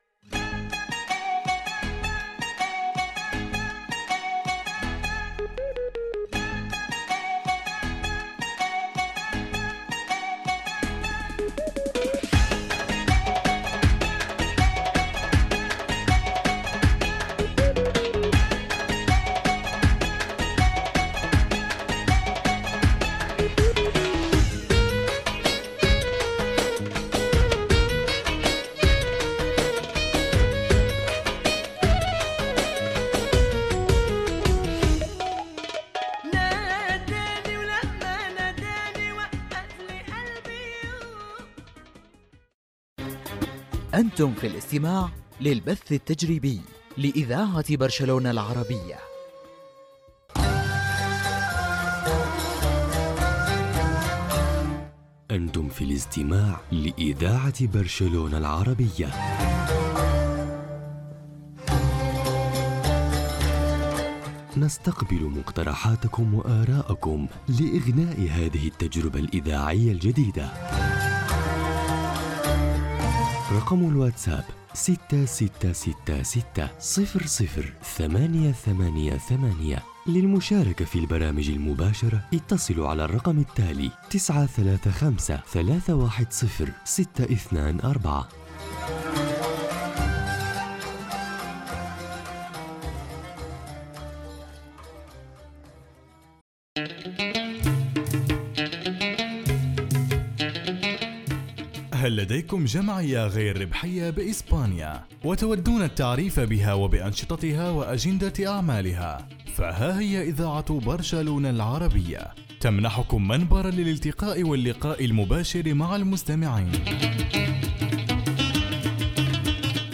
Música, identificació, programació